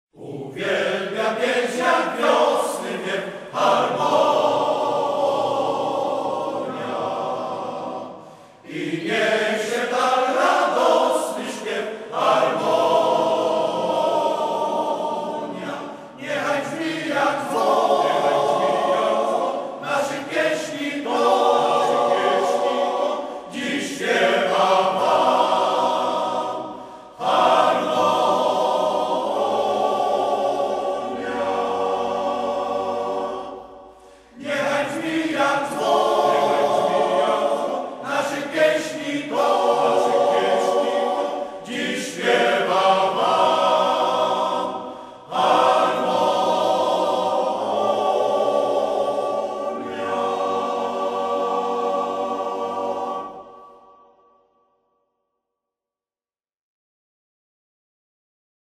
W listopadzie 1965 roku następuje reorganizacja i powstaje chór męski.
posłuchaj "Hasła" w wykonaniu Harmonii